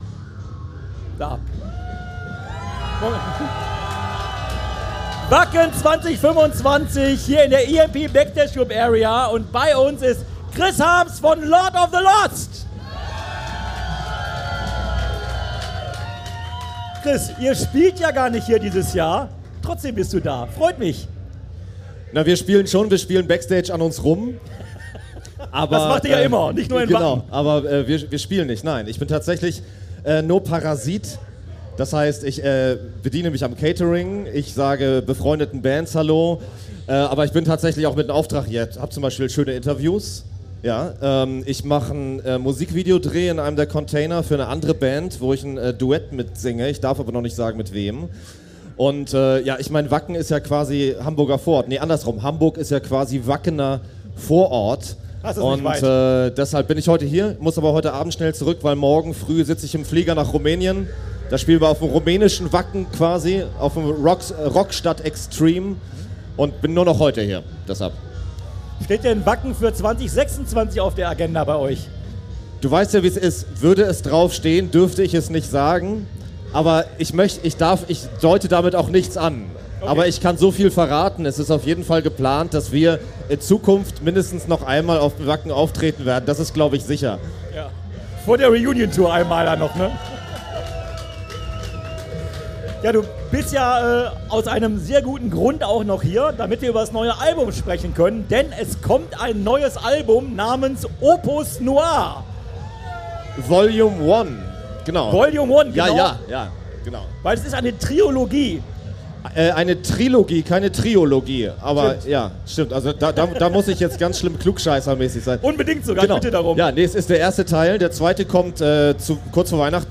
Wacken 2025 Special - Chris Harms - Live aus der EMP Backstage Club Area